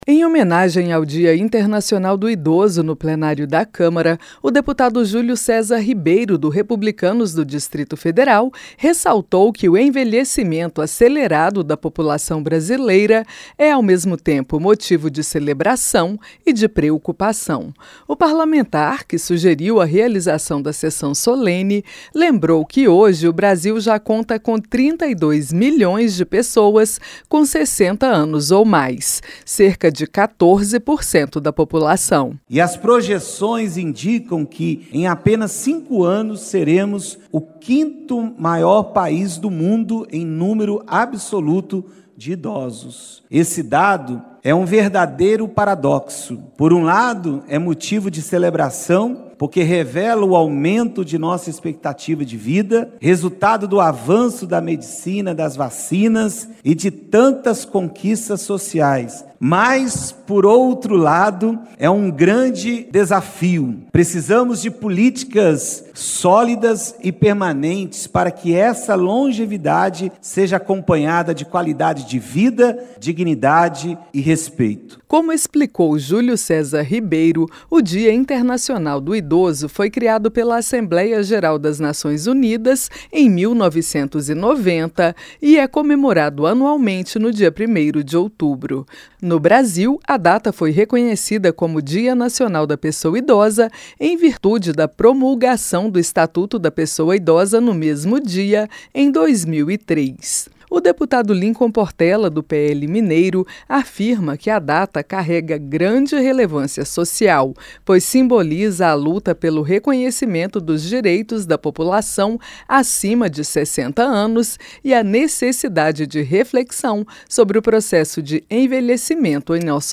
SESSÃO SOLENE DA CÂMARA HOMENAGEIA DIA INTERNACIONAL DO IDOSO.